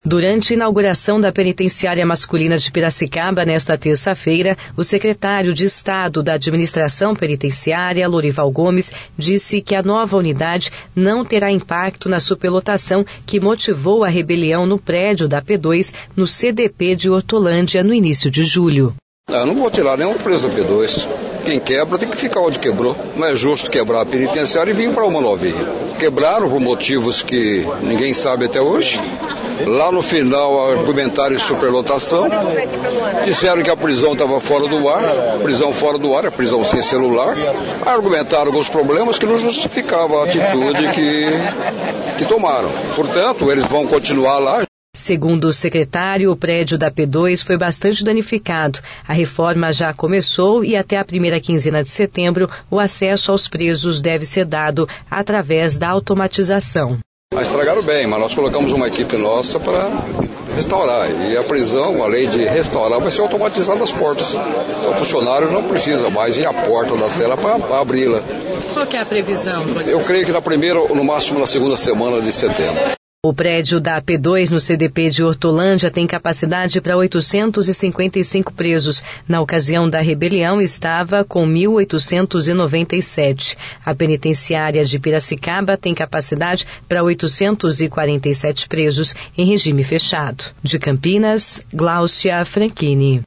Durante inauguração da penitenciária masculina de Piracicaba, nesta terça-feira (26/07), o secretário de Estado da Administração Penitenciária, Lourival Gomes, disse que a nova unidade não terá impacto na superlotação que motivou rebelião no prédio da P2 no CDP de Hortolândia, no início de julho.